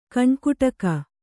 ♪ kaṇkuṭaka